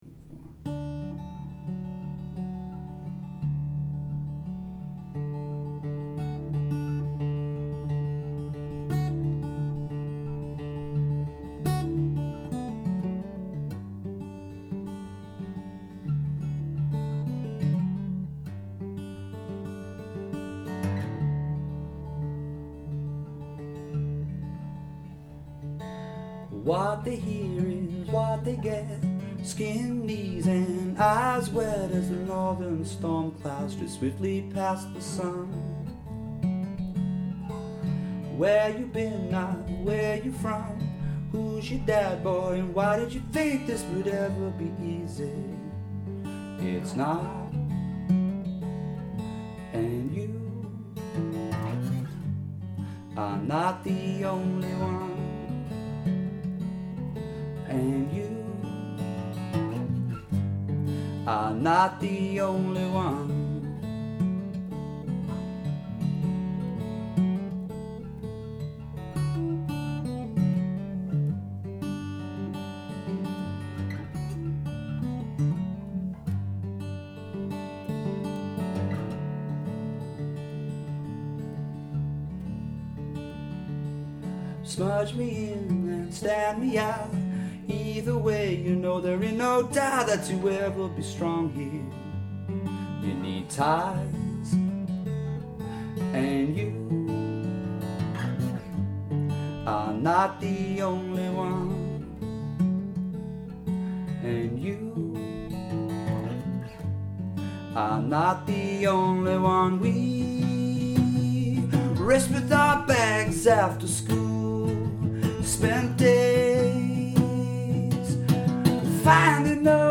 Another one in open D, recorded on my little Zoom personal recorder, in my bedroom
acoustic bass
Sometimes-reverbcomp1.mp3